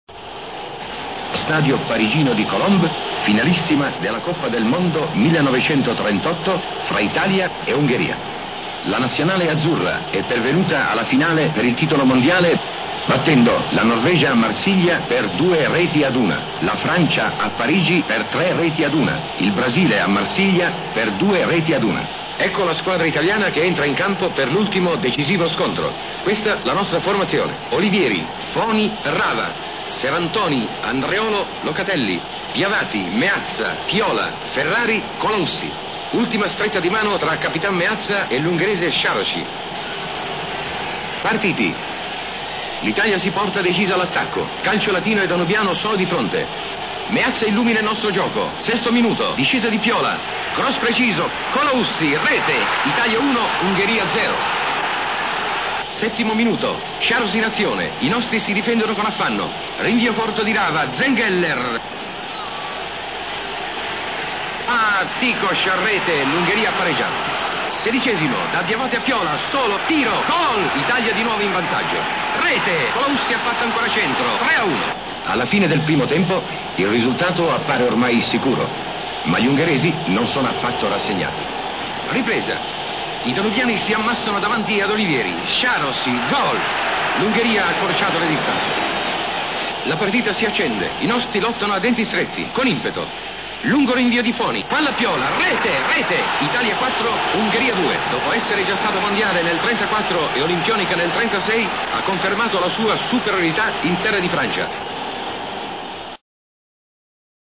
Chiunque abbia almeno cinquant'anni sa tuttavia di che cosa parliamo quando parliamo di Carosio: un voce pastosa, suggestiva senza essere stentorea, il tratto elegante e mai affannato, una serie di invenzioni linguistiche, pi� o meno intenzionali, che si riassumono nel celeberrimo "quasi gol".